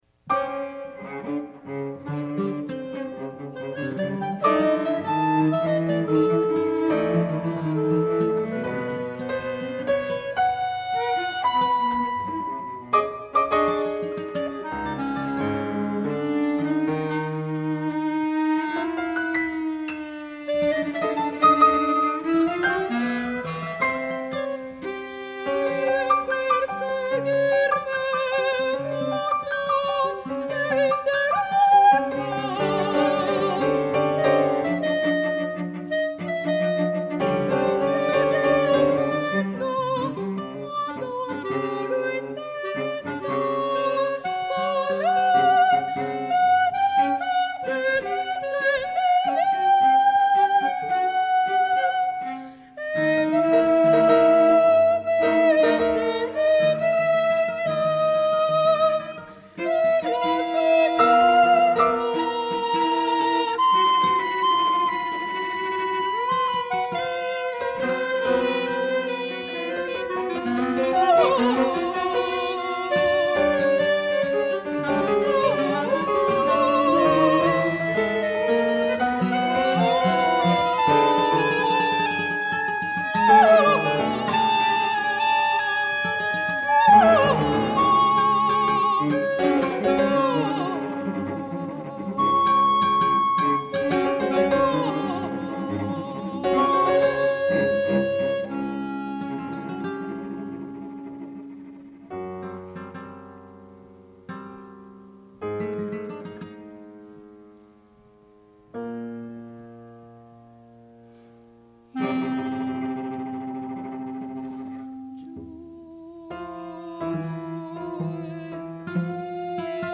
Soneto I. Para soprano, clarinete, guitarra, piano y violonchelo. (Versión del concierto de estreno)